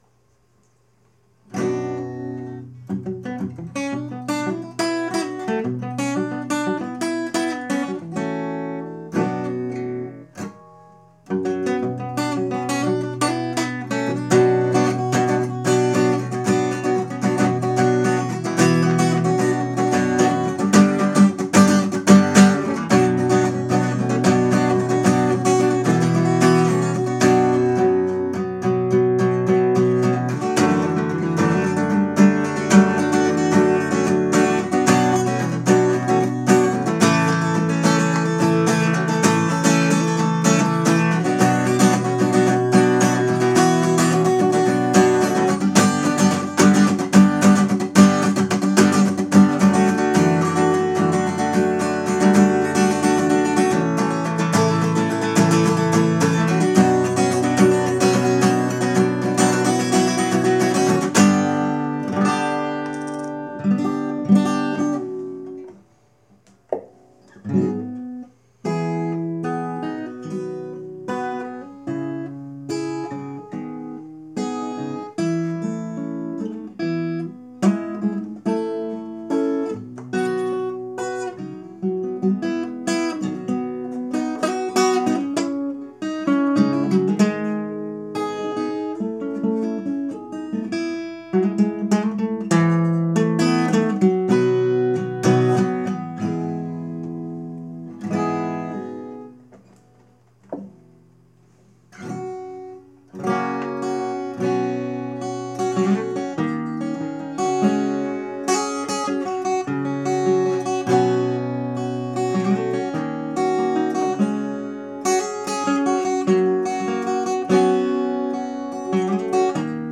I have a Martin without any sort of pickup, so there's no way to plug it directly in.
It turns out the built-in mic is totally reasonable. It's not nearly the same as a professional mic in a sound booth, but it's enough that you can hear what's being played. This was done in one take and it's all improv, so there's a bit of meandering. You can also hear me changing the capo twice.
Acoustic Sample in GarageBand (2:39 / 2.42MB)